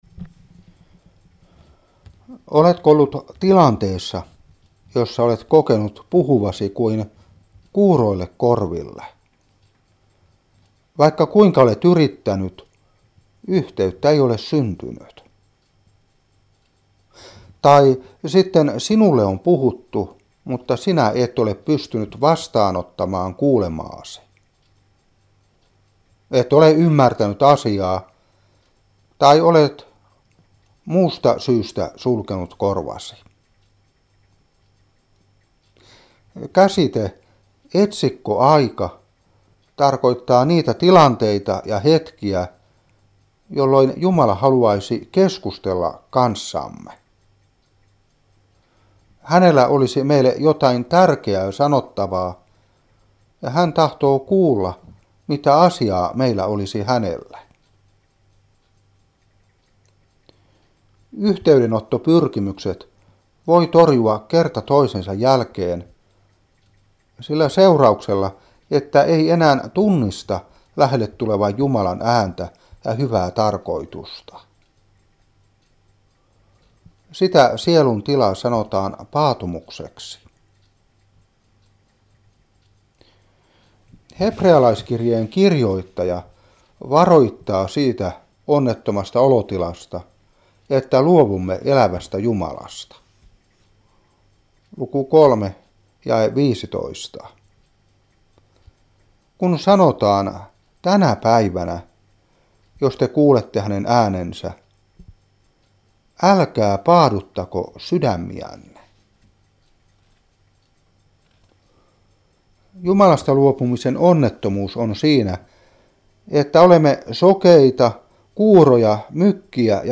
Seurapuhe 2015-8.